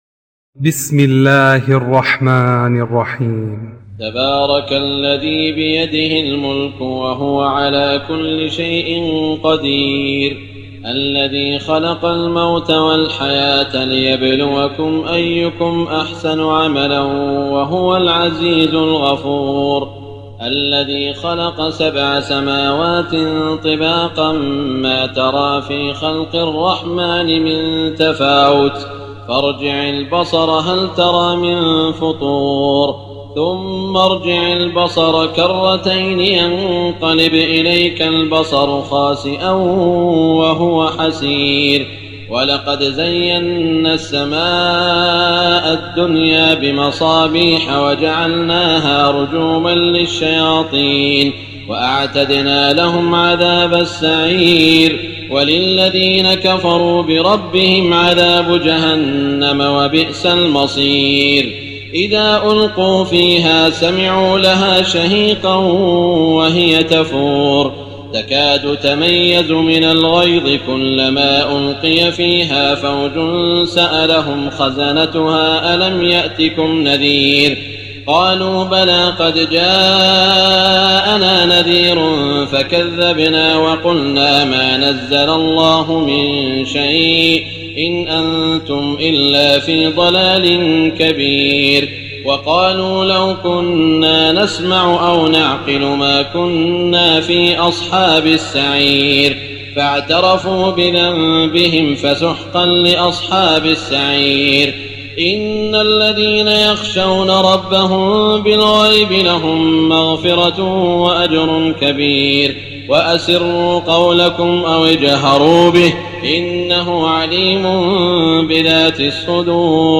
تراويح ليلة 28 رمضان 1419هـ من سورة الملك الى نوح Taraweeh 28 st night Ramadan 1419H from Surah Al-Mulk to Nooh > تراويح الحرم المكي عام 1419 🕋 > التراويح - تلاوات الحرمين